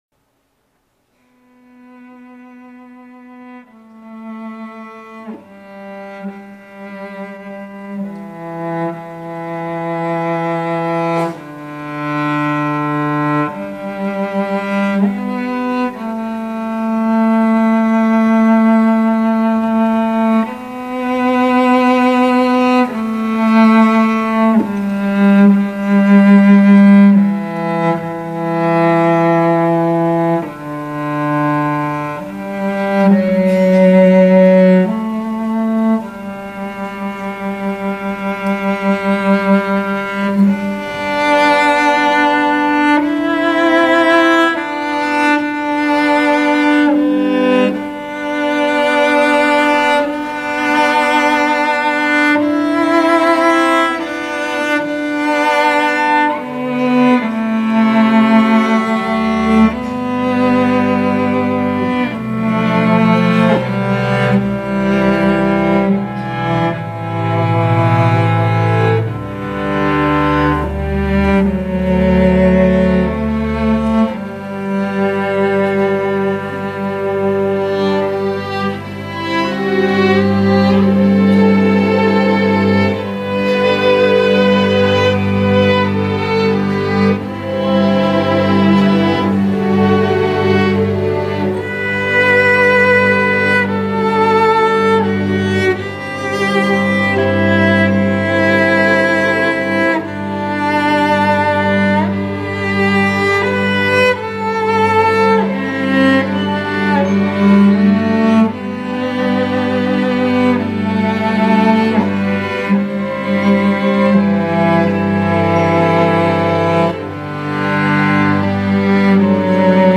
GoodNewsTV Program 성가공연 내 주를 가까이 하게 함은
스페인어로 ‘감사’라는 뜻을 지닌 이름을 가진 ‘그라시아스 합창단’이 선사하는 성가 공연은 가사와 멜로디 속에 담긴 감동과 영감을 그대로 전달하며, 하나님에 대한 무한한 감사와 찬양을 돌린다.